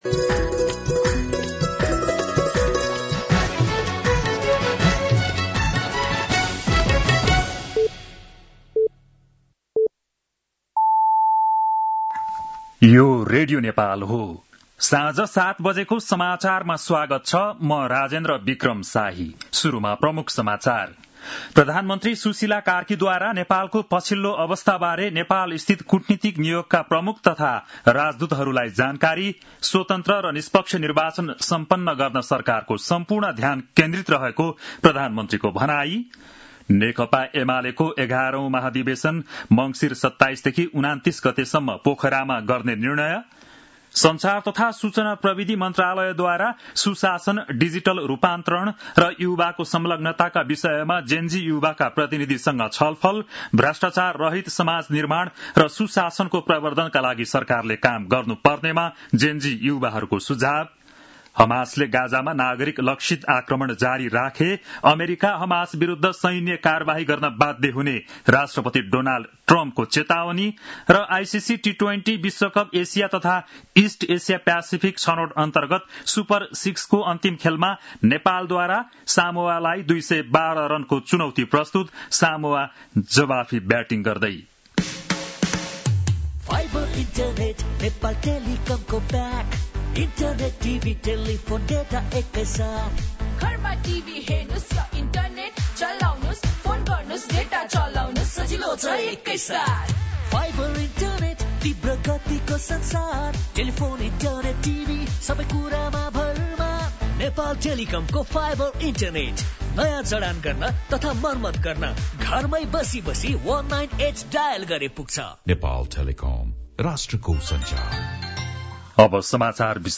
बेलुकी ७ बजेको नेपाली समाचार : ३१ असोज , २०८२